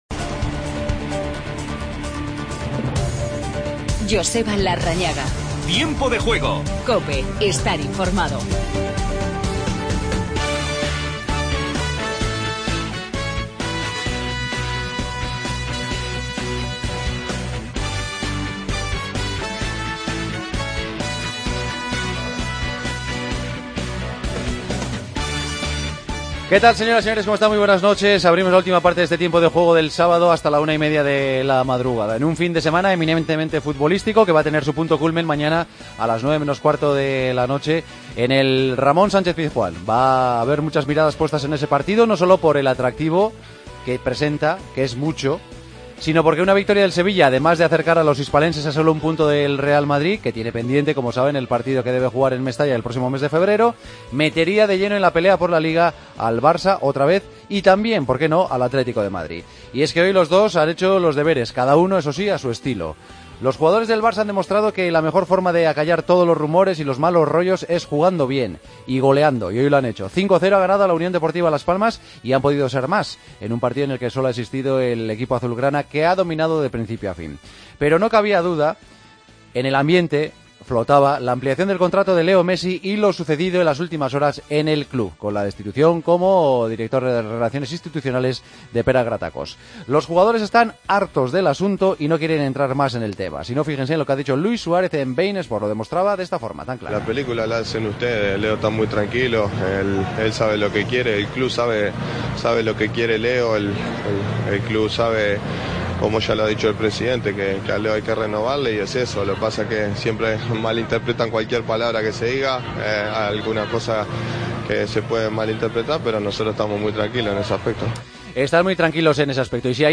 Titulares del día. Un Barcelona con rotaciones golea con facilidad a Las Palmas. Gaitán le da al Atleti una victoria por la mínima.
Hablamos con Arribas y Gabi. Escuchamos a Busquets.